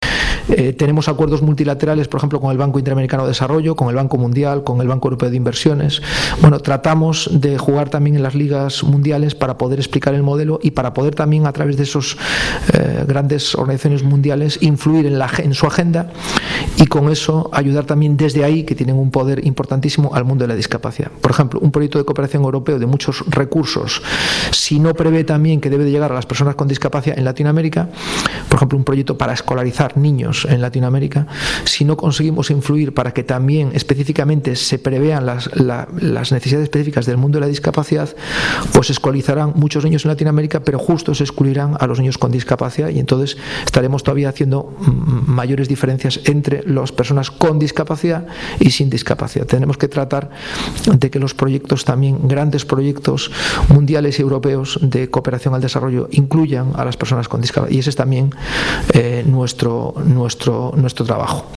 Ante una audiencia que llenó a rebosar el Salón Sardinero del Hotel Real de Santander